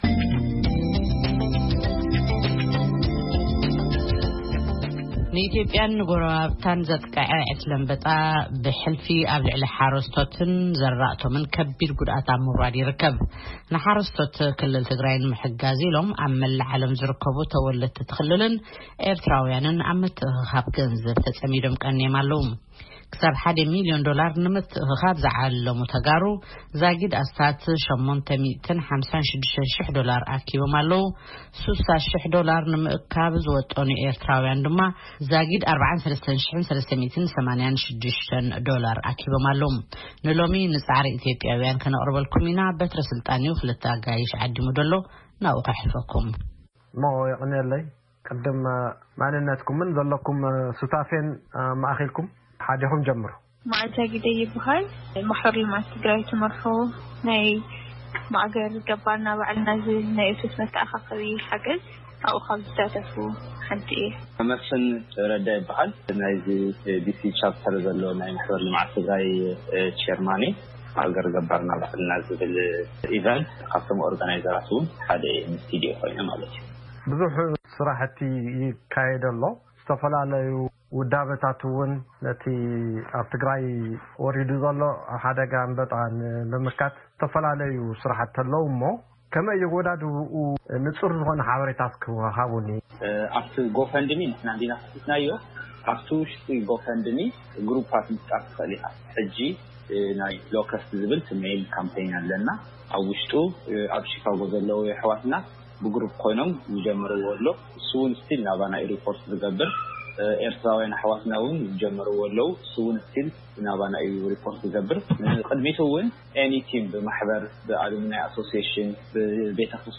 ቃለ ምልልስ ብዛዕባ ምትእኽኻብ ገንዘብ